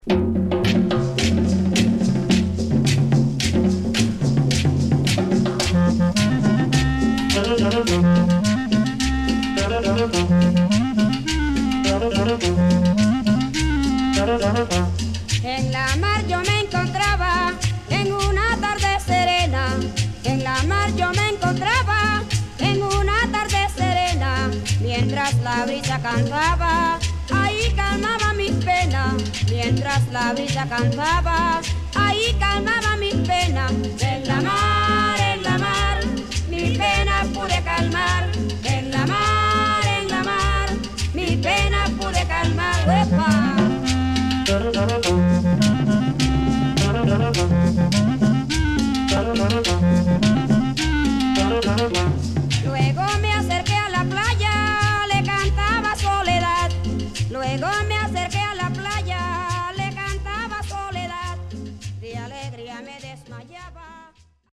パーカッション主体にアコーディオンのメロディーライン。そしてベースラインはレゲエにも似た所があるというクンビア。